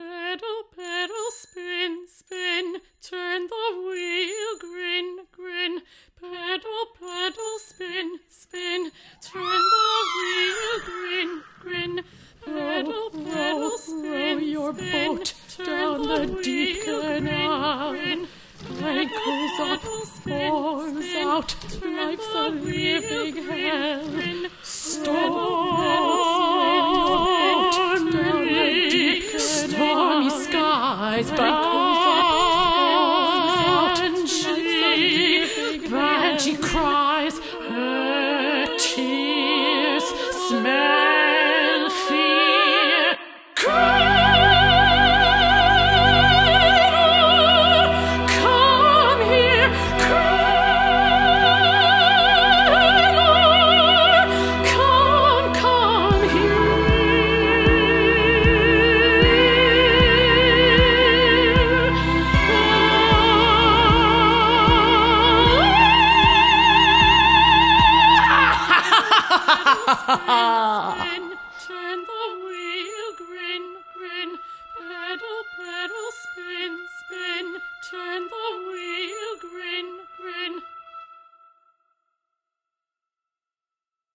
title track for a VR game